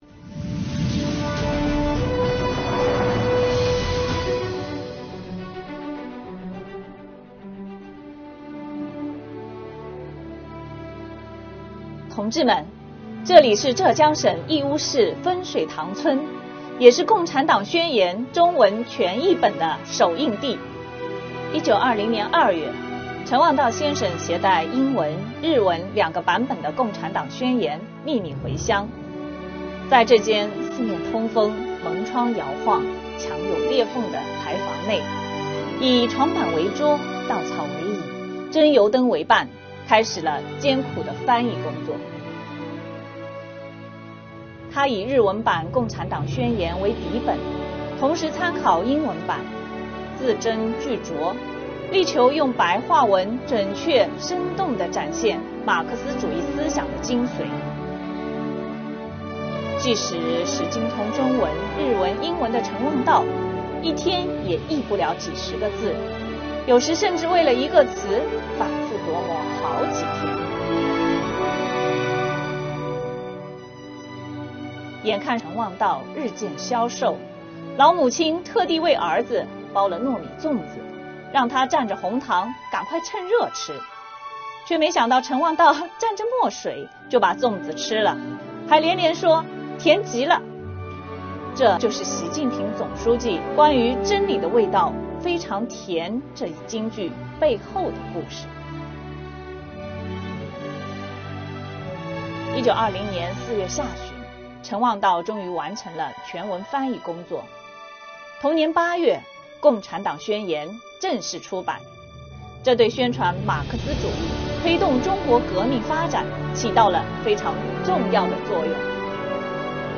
今天跟随浙江税务干部来到《共产党宣言》中文全译本首译地——浙江省义乌市分水塘村，了解《共产党宣言》中文译本的诞生过程，品味“真理的味道”，在精神之甘、信仰之甜中为高质量推进税收现代化汲取奋斗力量。